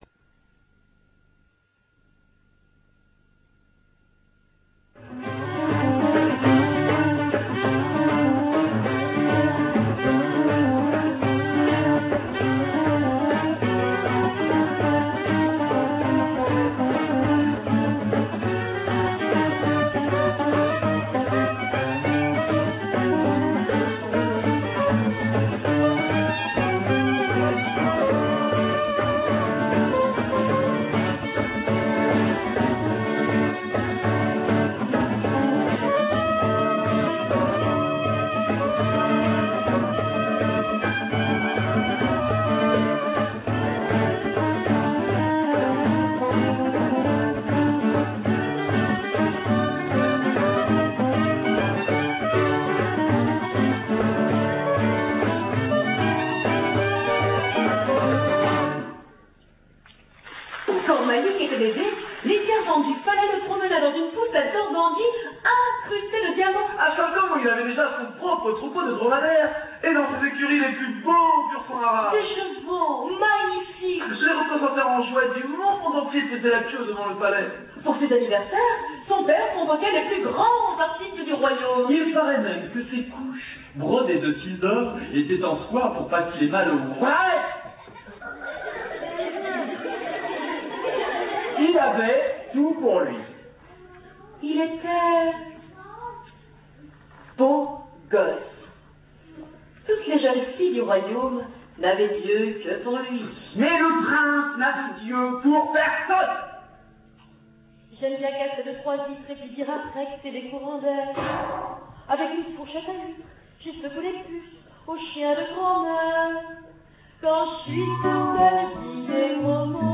LE SPECTACLE "QUI A DIT CHAT" COMEDIE MUSICALE TOUT PUBLIC
Extraits du spectacle 3mo ( Vid�o 11 minutes ) modem 56 pour lire la video il vous faut télécharger le lecteur real one Extraits du spectacle 24mo (Vid�o 11 minutes) Adsl ou cable video pour windows media player